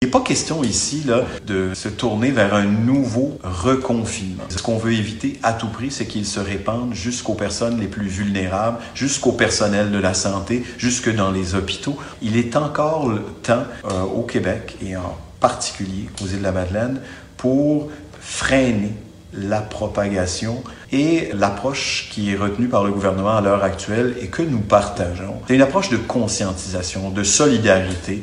Le député des Îles à l’Assemblée nationale, Joël Arseneau, a réagi à l’annonce de lundi par vidéo sur les réseaux sociaux et appelle les Madelinots au respect des règles sanitaires :